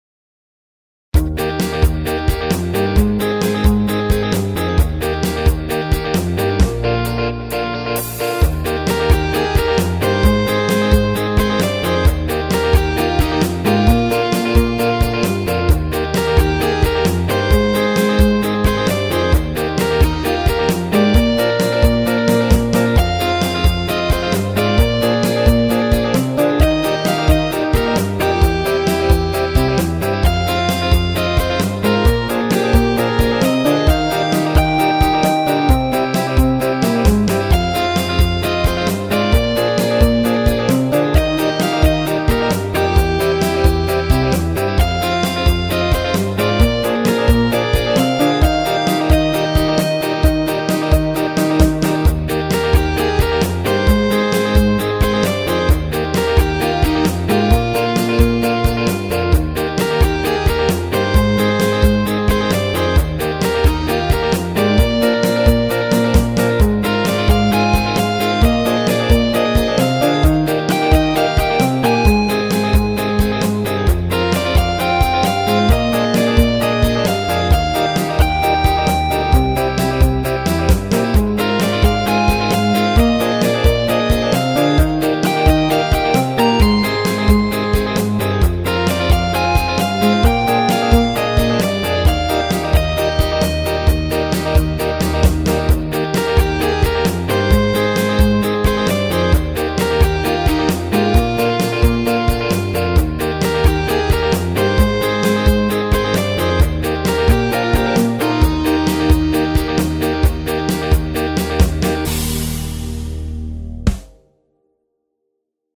ロックロング明るい